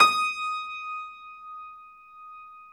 53d-pno19-D4.wav